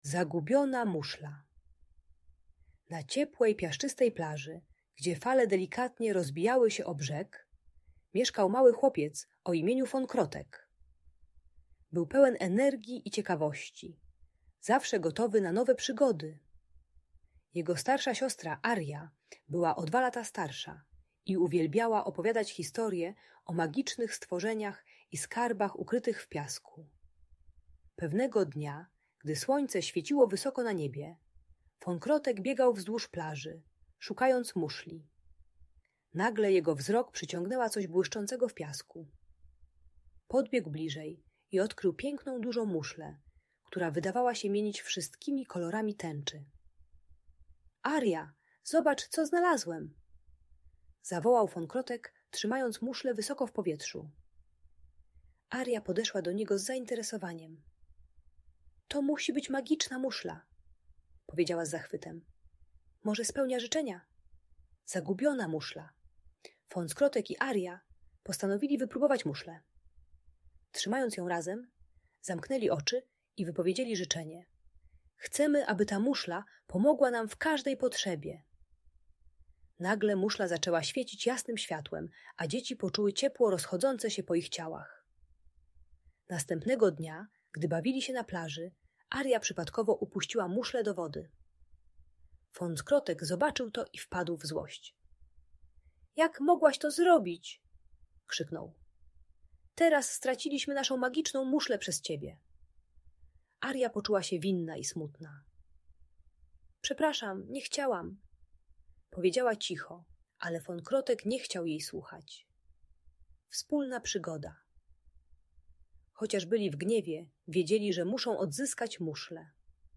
Przygody Fonckrotka i Arii - Audiobajka